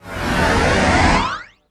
ZZZOOOMMM.wav